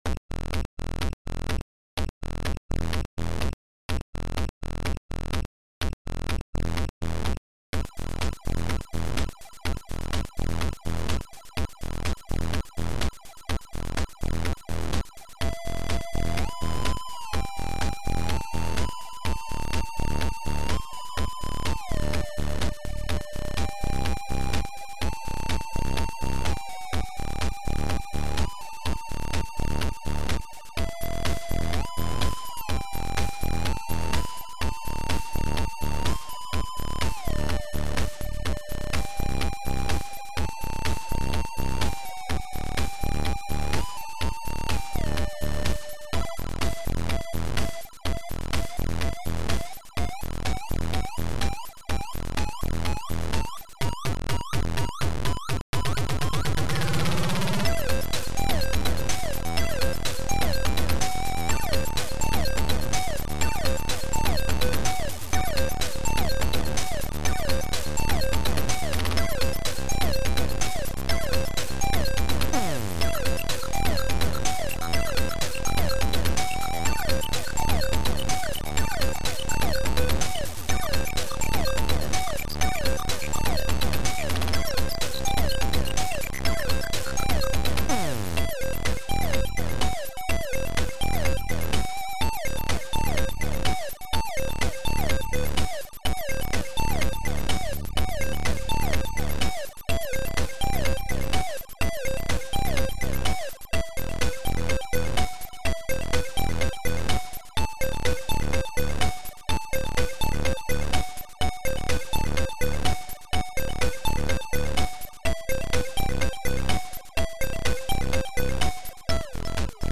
hardtrance tune